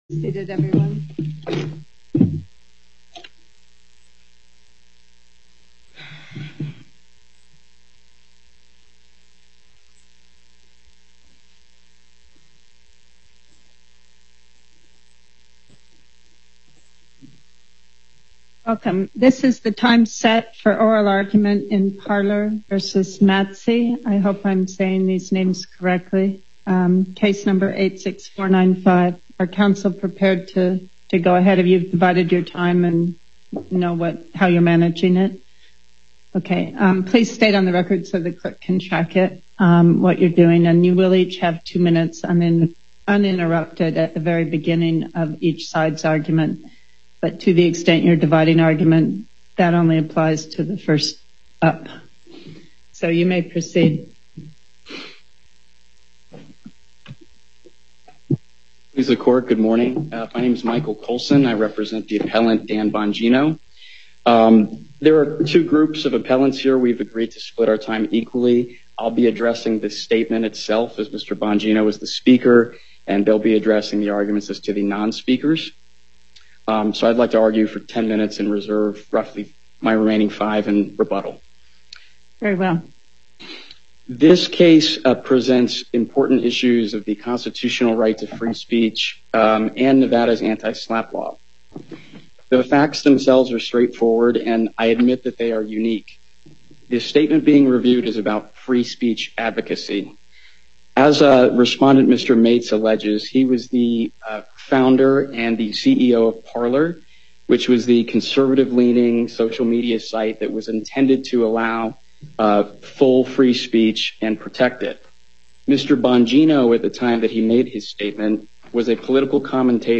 Before Panel B25, Justice Pickering presiding